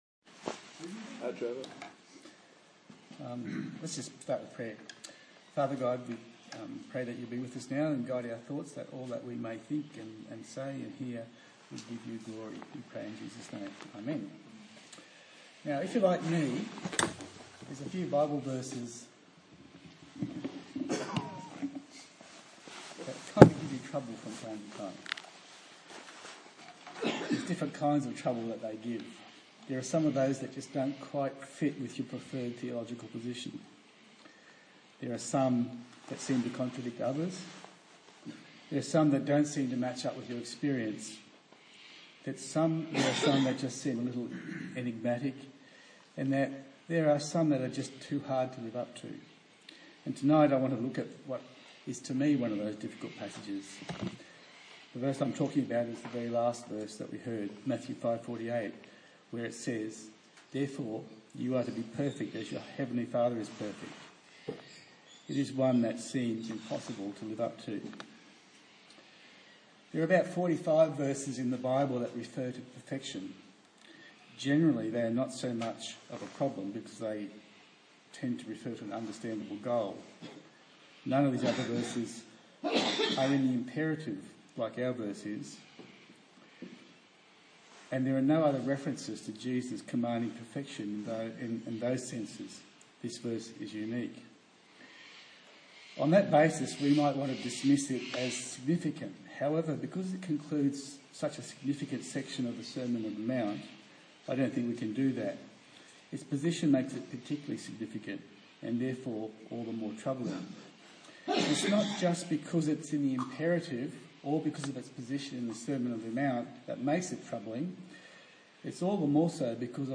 A sermon on the book of Matthew